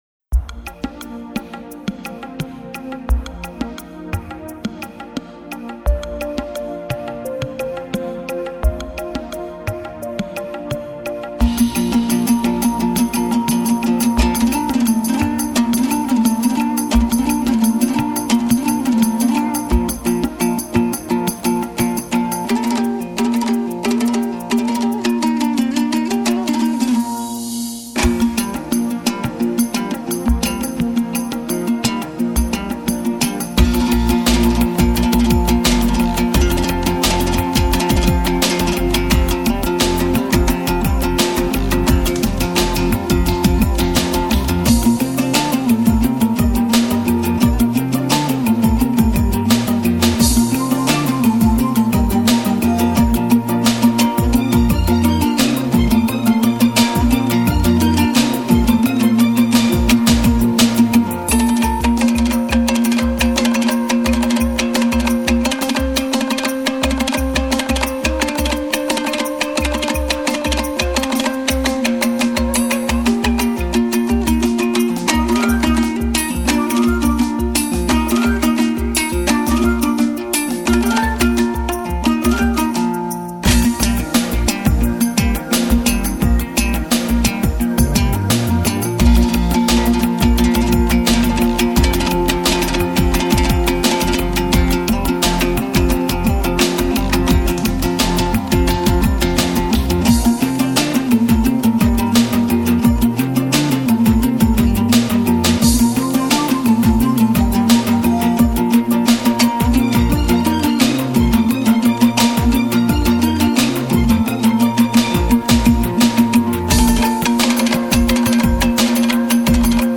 это произведение в жанре казахской поп-музыки